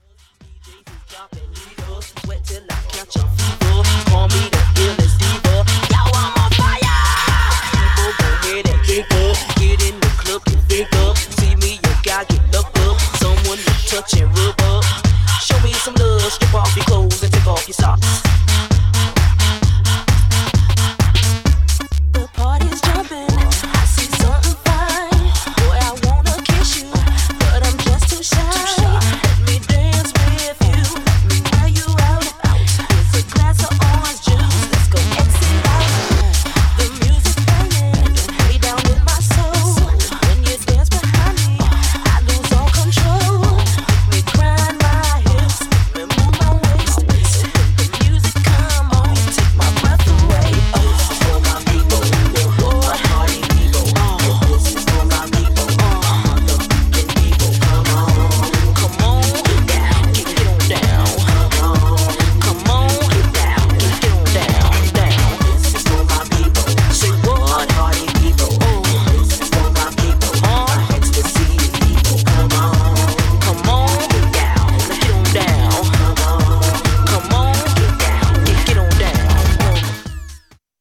Styl: Hip Hop, House